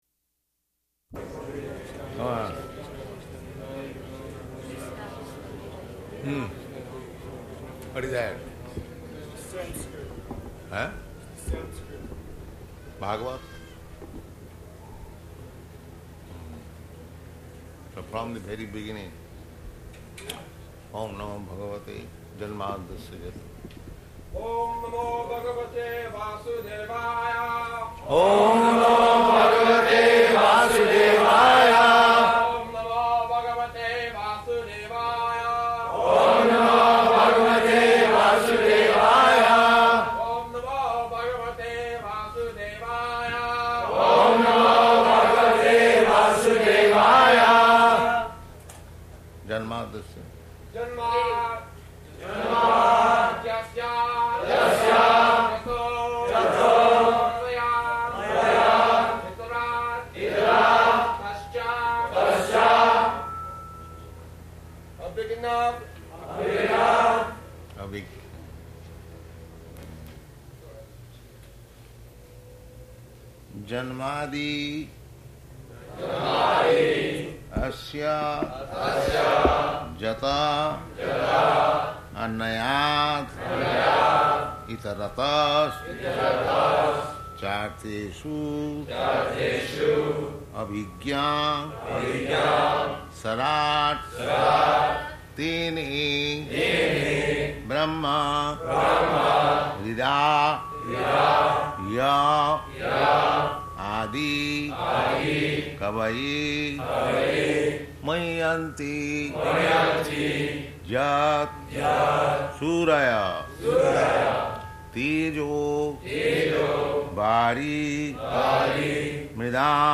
Śrīmad-Bhāgavatam 1.1.1 --:-- --:-- Type: Srimad-Bhagavatam Dated: July 6th 1972 Location: London Audio file: 720706SB.LON.mp3 Prabhupāda: Come on.